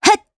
Xerah-Vox_Attack1_jp.wav